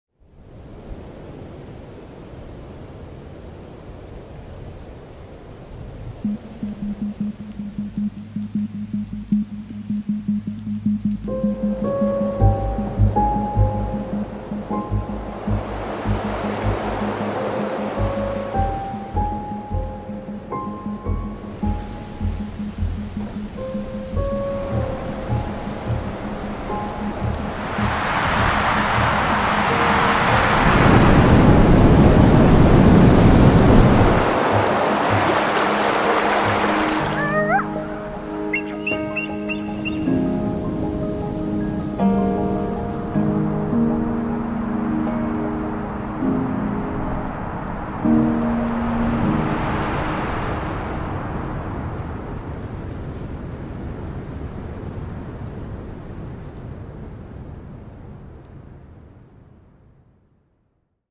我们已经记录了最有趣和最具标志性的氛围，这是尽可能准确地真实反映地球声音特征的唯一方式：使用无与伦比的Schoeps ORTF3D环绕声套件。
现在可在沉浸式3D环绕声中使用。
随着大雪覆盖着广阔的土地，野生生物适应了恶劣的条件，周围环境的特征得到了变化。
平静的微风，寒冷的音调，飘落的雪花的微弱声音，在不同距离记录的冰冻河流，刺骨的冬季风暴和微妙的动物活动。